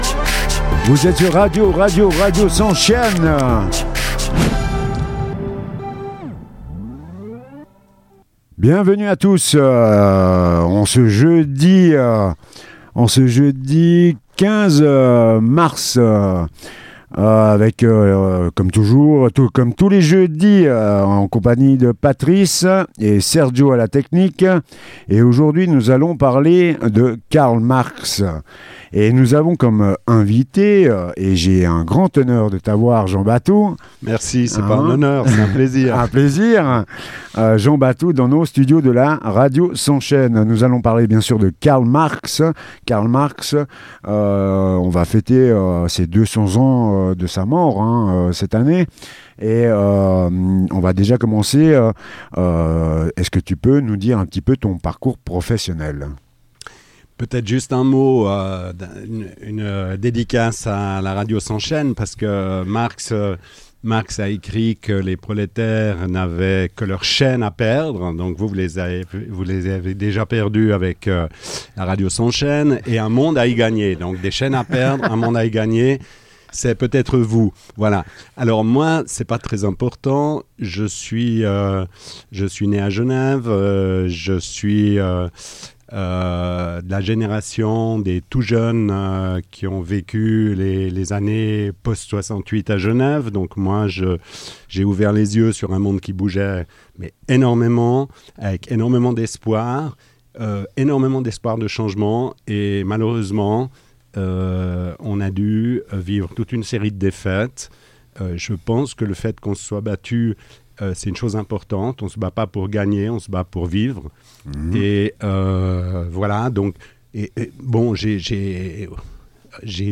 Interview
L’émission